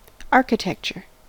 architecture: Wikimedia Commons US English Pronunciations
En-us-architecture.WAV